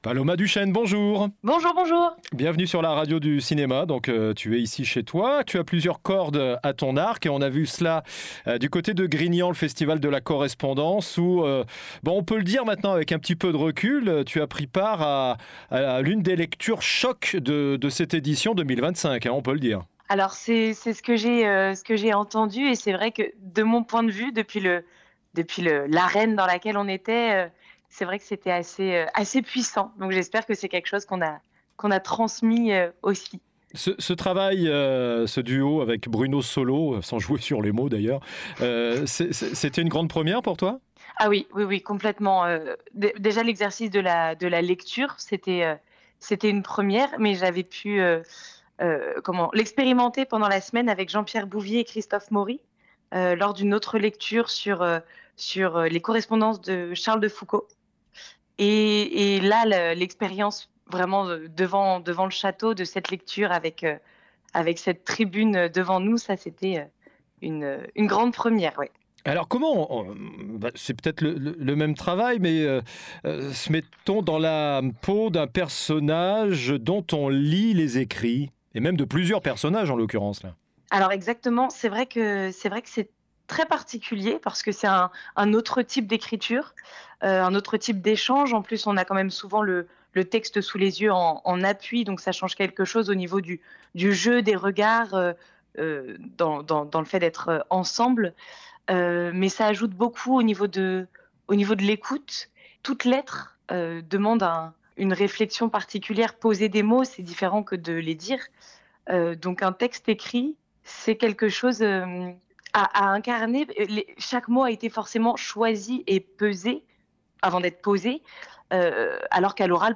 Derrière la voix posée, une passion brûle : celle du cinéma.